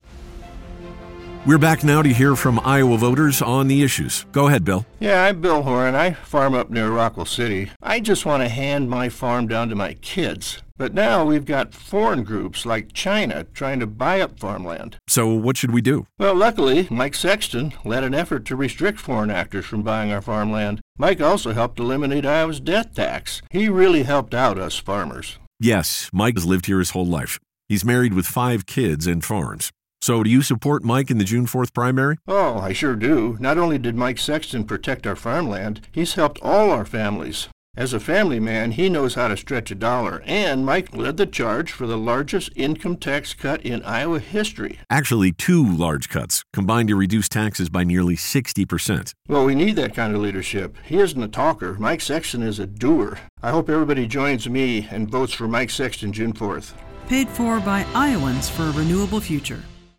a radio ad that urged listeners to vote for Sexton because of his work to cut taxes and restrict foreign ownership of farmland.